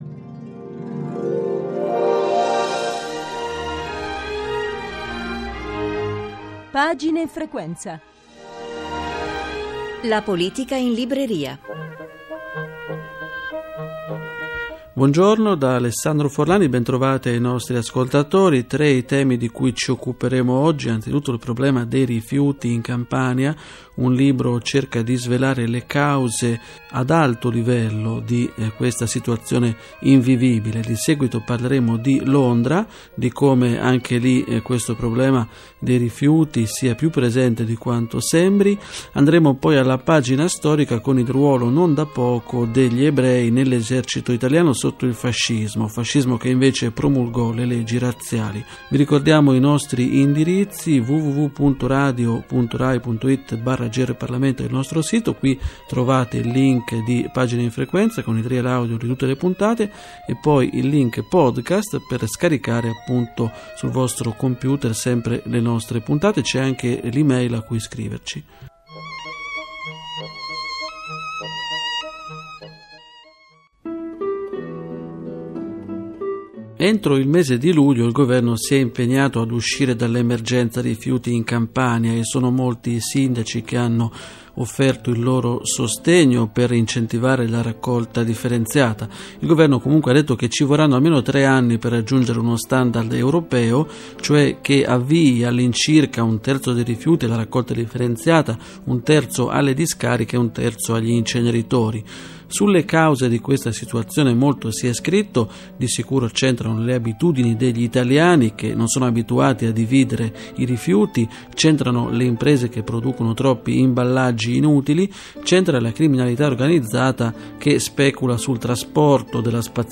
Registrazione della trasmissione Pagine in frequenza :"La politica in libreria"